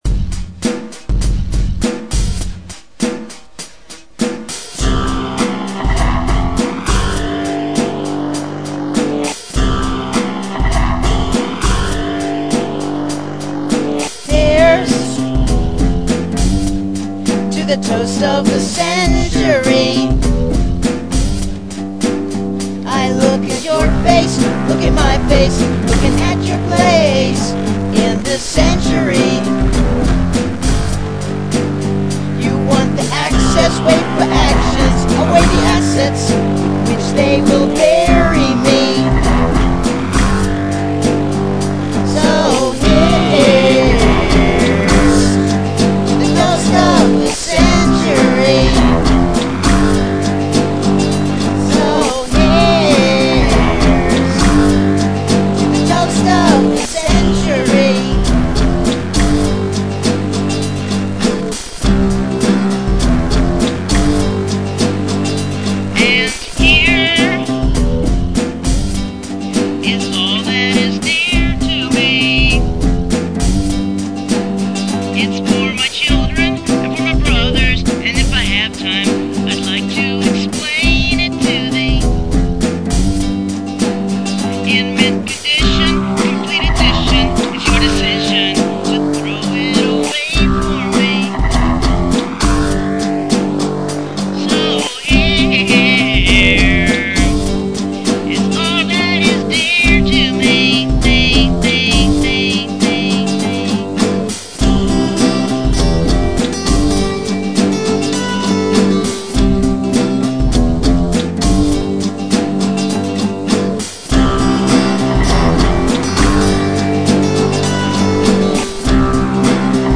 Nashville Nerdrock